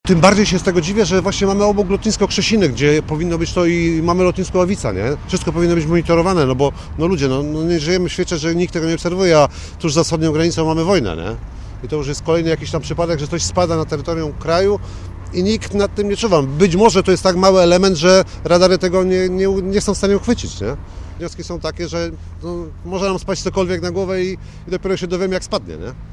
Mieszkaniec Komornik